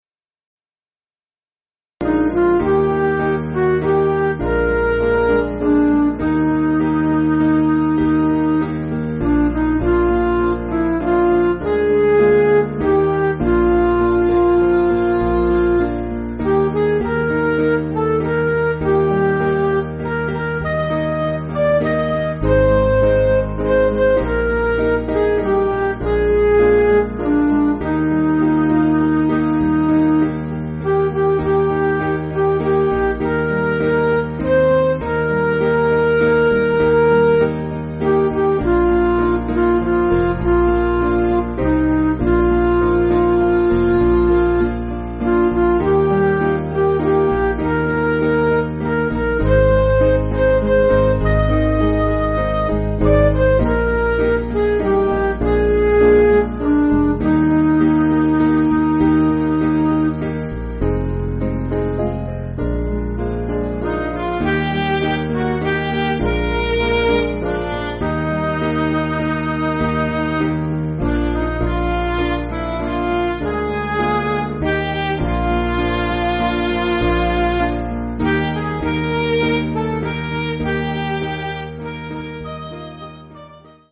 Piano & Instrumental
(CM)   3/Eb